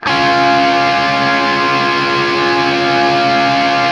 TRIAD C# L-L.wav